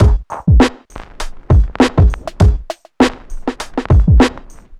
nu funky 100bpm 03.wav